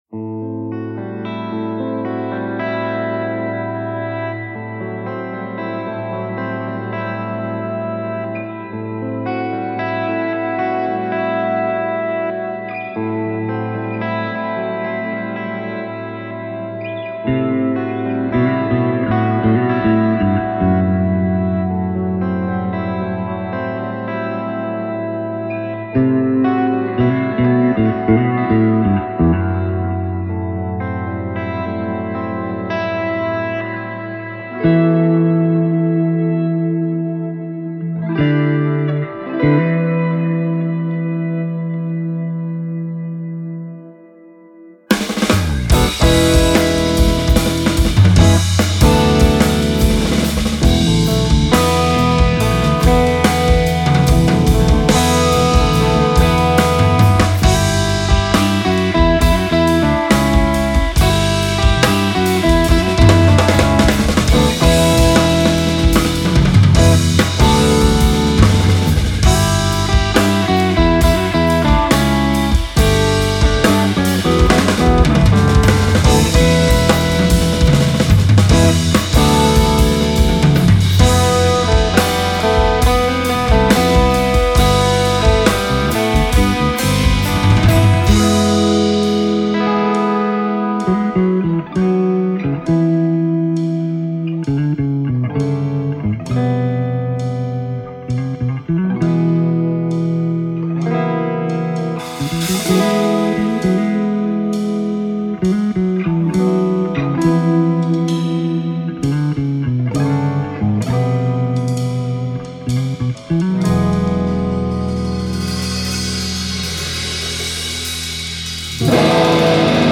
Genere: Fusion.